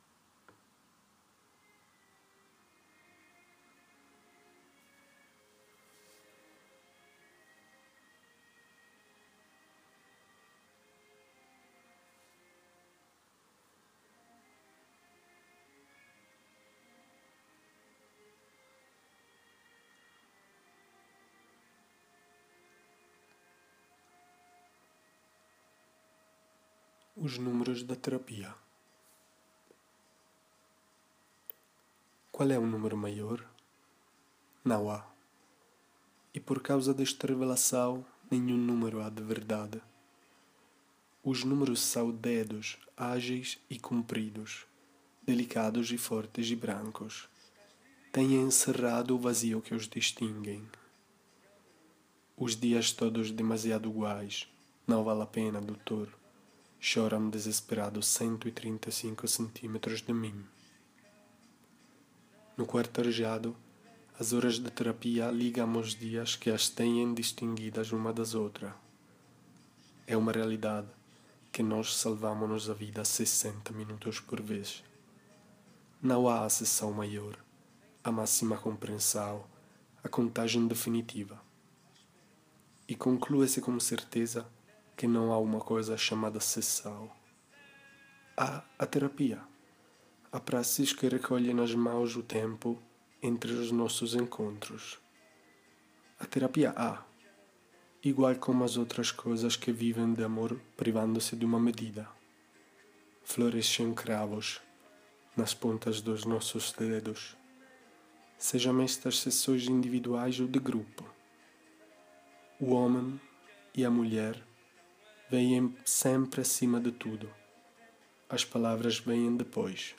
traduzione e lettura